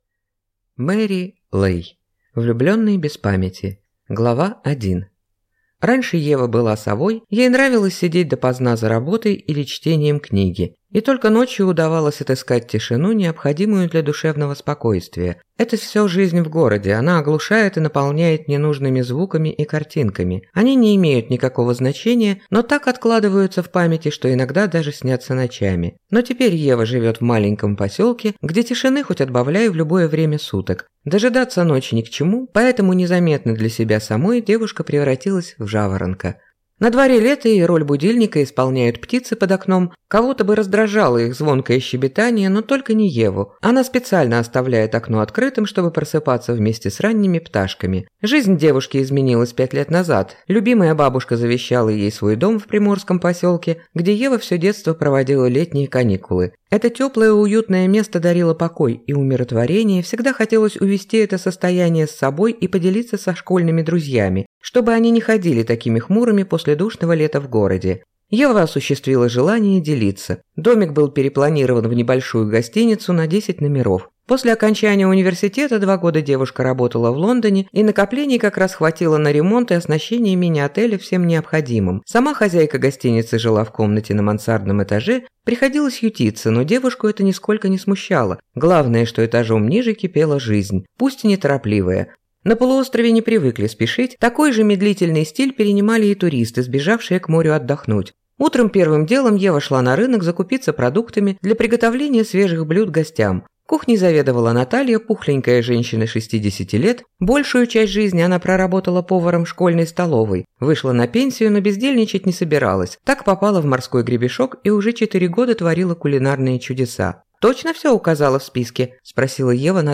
Аудиокнига Влюбленный без памяти | Библиотека аудиокниг